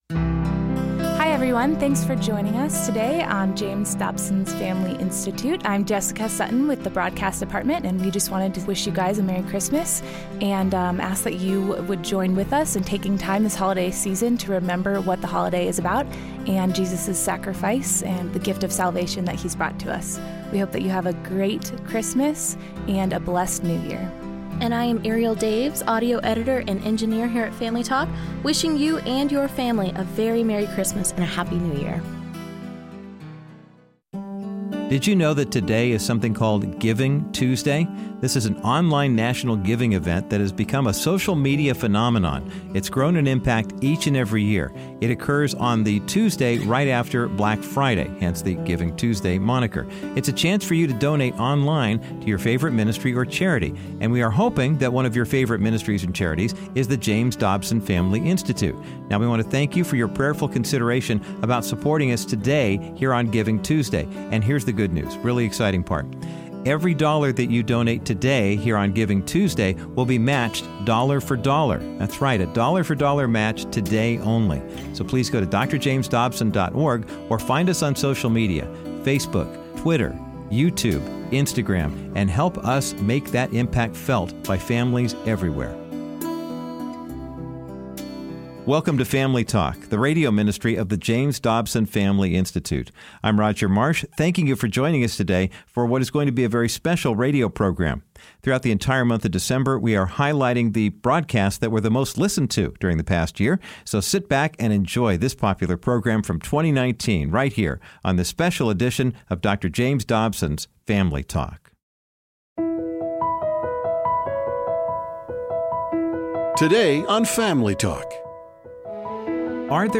On this popular edition of Family Talk, Dr. Dobson continues his discussion with Rabbi Jonathan Cahn, author of The Oracle. Rabbi Cahn describes how the Jews return to Israel was put into motion because of Mark Twain, President Truman, and the aftermath of World War II.